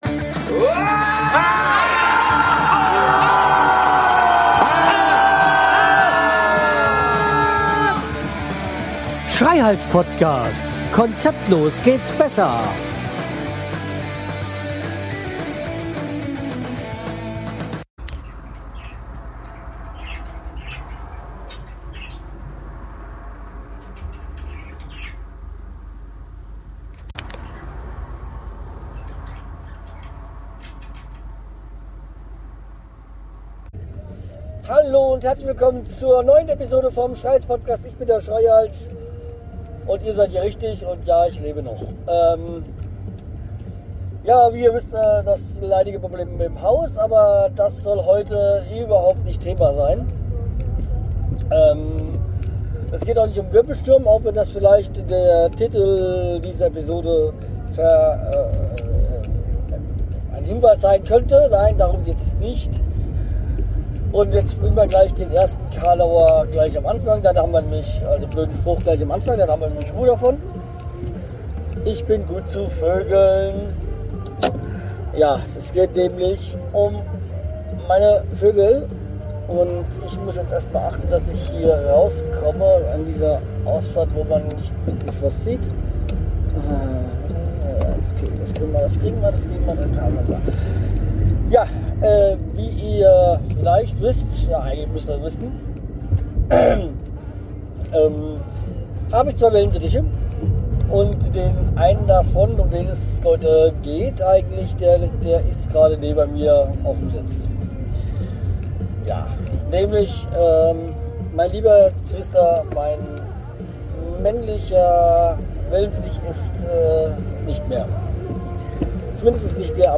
Sorry, für die eher durchschnittliche Tonqualität.